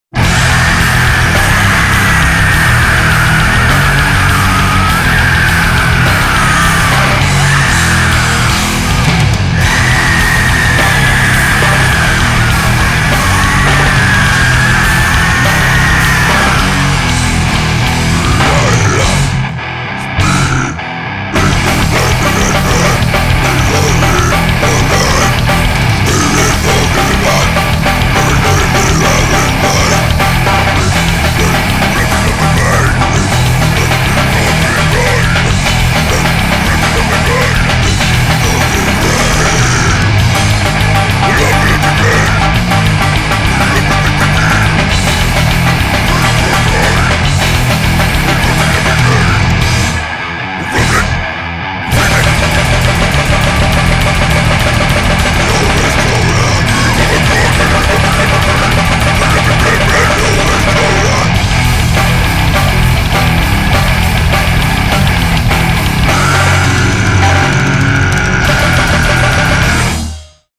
boost bass
guitar
drums
vocals